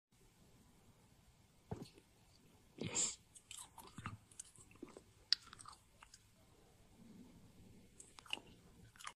Grape Eating 🍇 ASMR Sound Effects Free Download